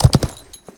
gallop2.ogg